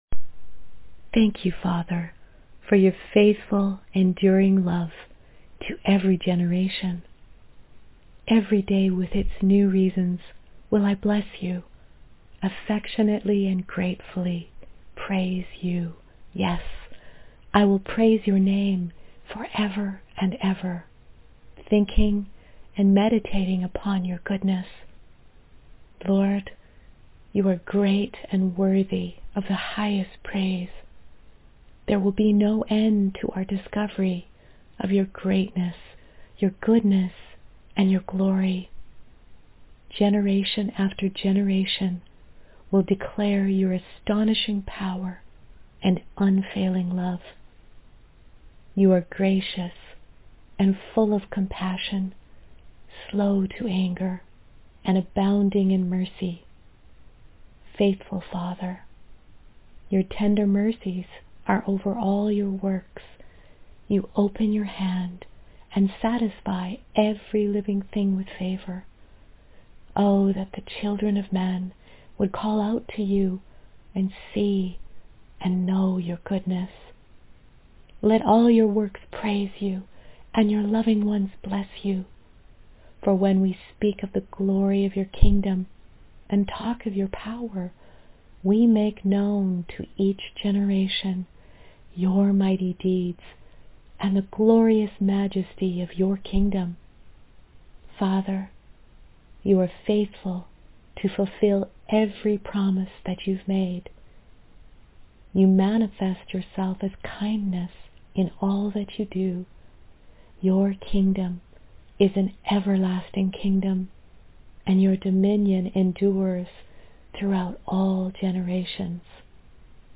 Psalm 145 & 37 Audio Prayer and Verse